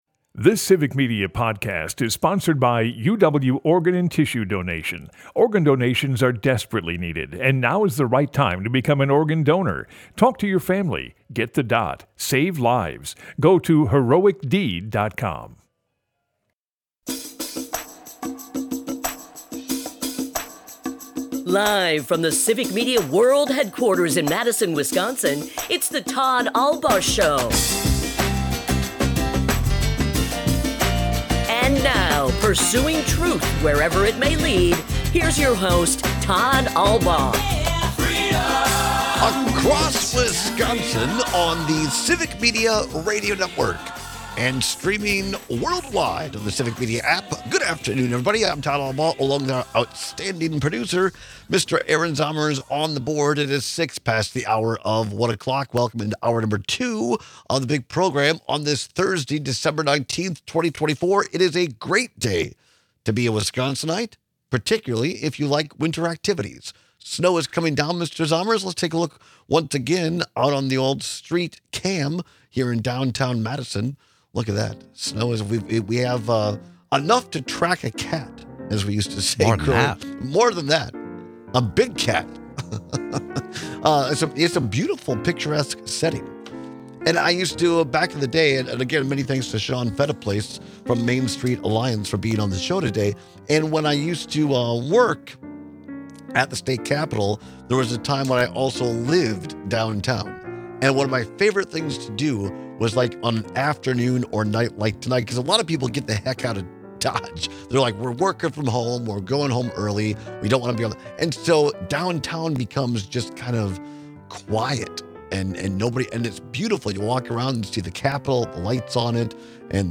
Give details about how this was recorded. Broadcasts live 12 - 2p across Wisconsin.